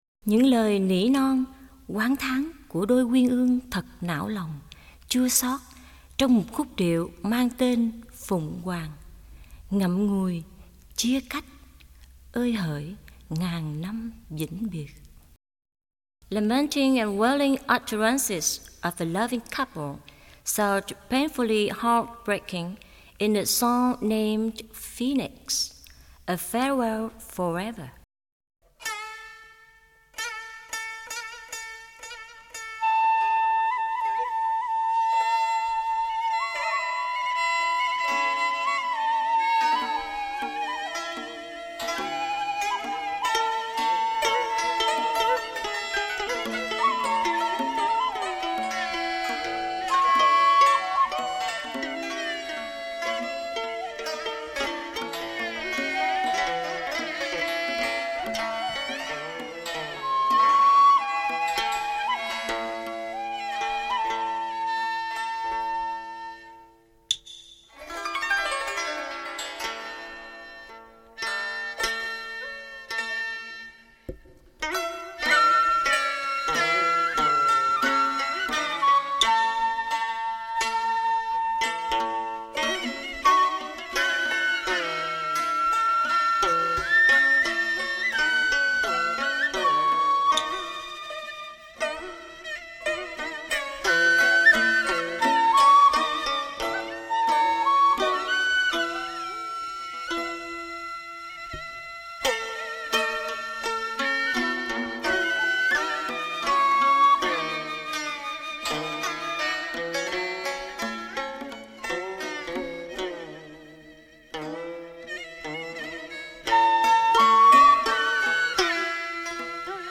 Hòa tấu: Tranh – Kìm – Cò – Tỳ – Bầu – Tiêu